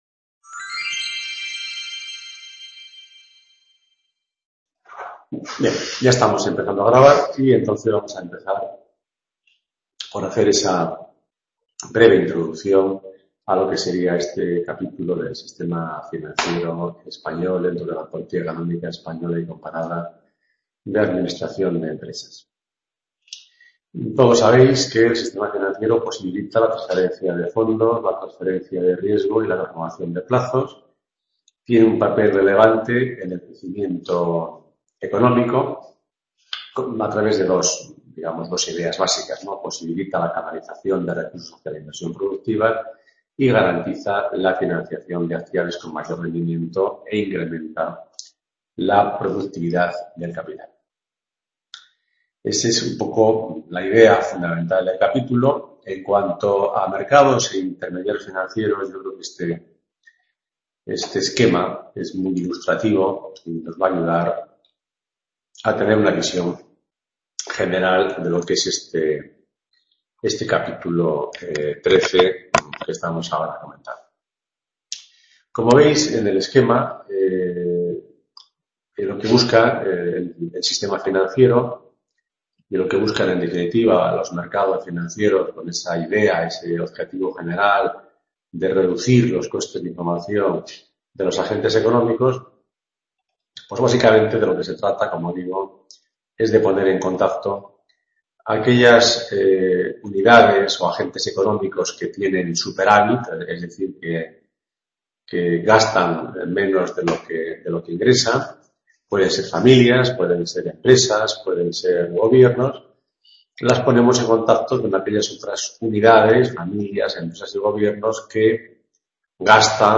Seminario de Política Económica Española y Comparada del Grado de Administración y Dirección de Empresas. Capítulo 13. El Sistema Financiero.